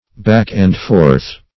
\back"-and-forth`\